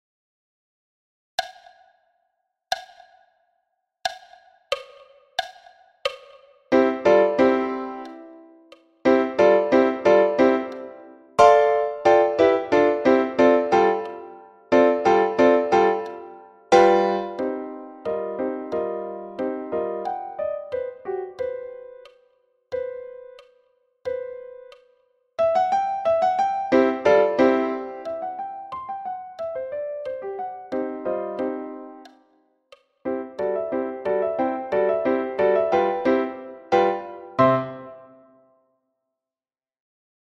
Farce – Piano à 90 bpm
Farce-Piano-a-90-bpm.mp3